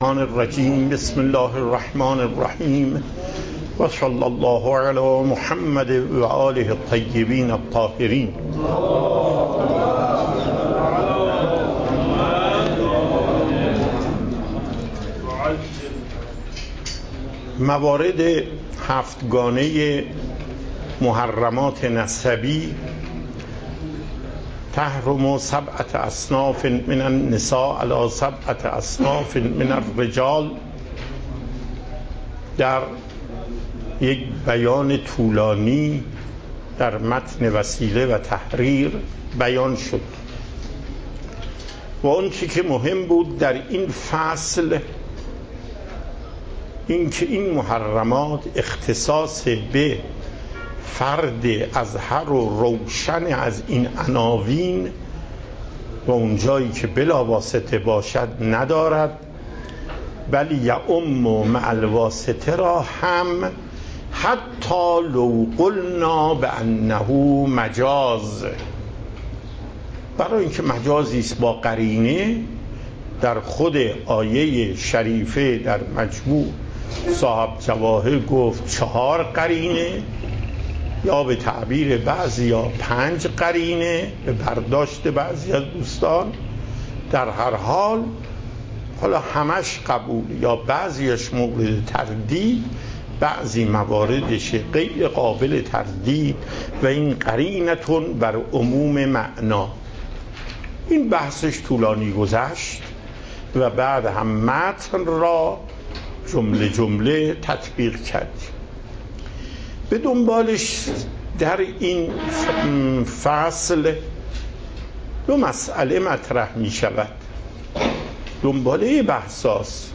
صوت و تقریر درس پخش صوت درس: متن تقریر درس: ↓↓↓ تقریری ثبت نشده است.
درس فقه آیت الله محقق داماد